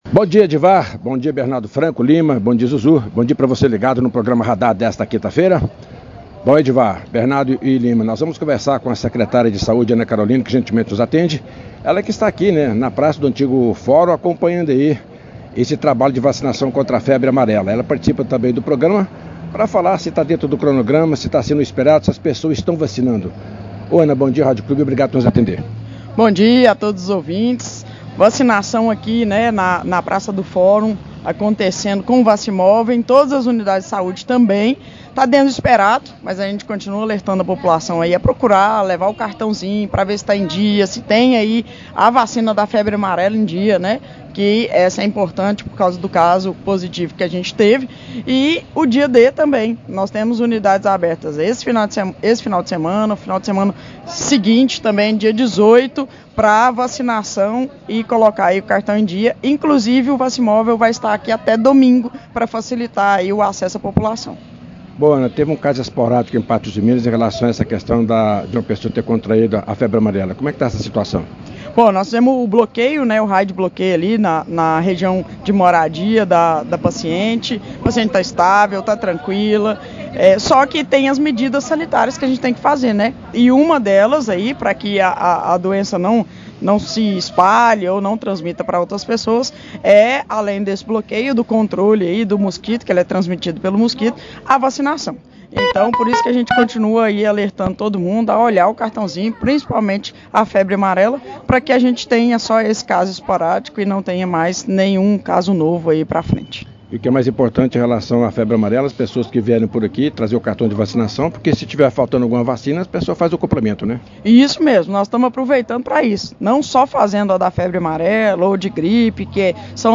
Na manhã desta quinta-feira (09), a Secretária Municipal de Saúde, Ana Carolina Caixeta, concedeu entrevista ao programa da Radar da Rádio Clube 98.
Entrevista-com-a-Secretaria-de-Saude-Ana-Carolina-Caixeta.mp3